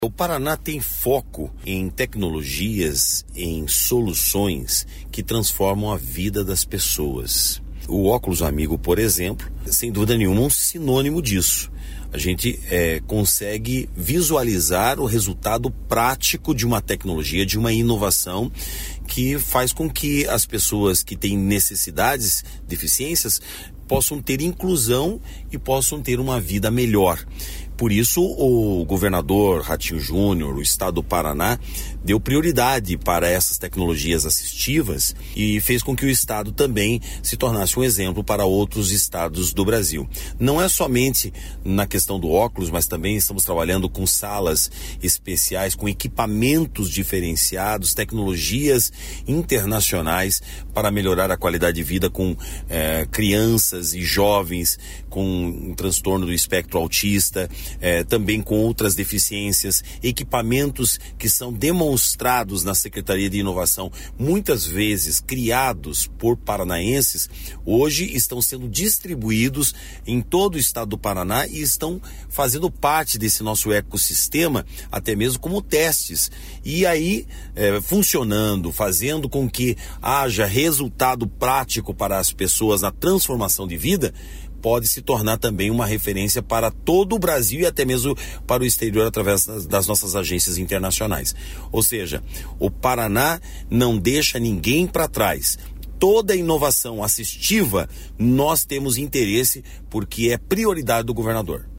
Sonora do secretário da Inovação, Modernização e Transformação Digital, Marcelo Rangel, sobre o avanço em tecnologias assistivas em 2023